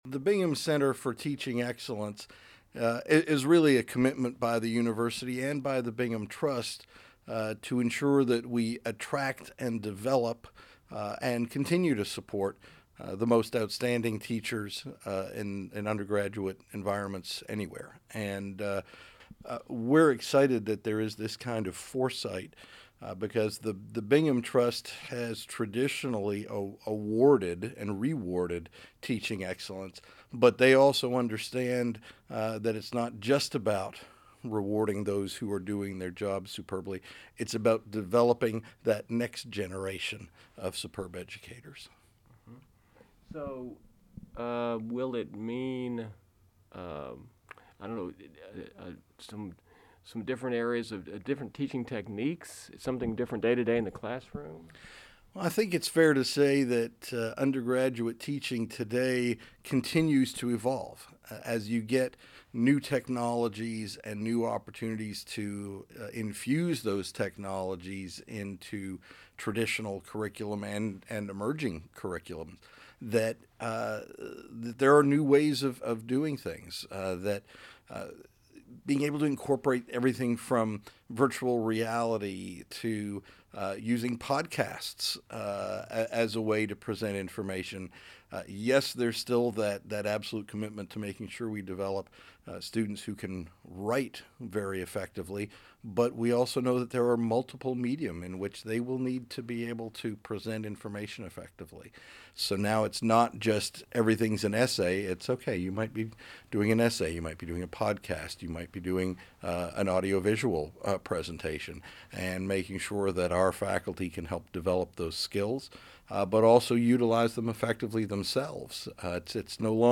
Here's the interview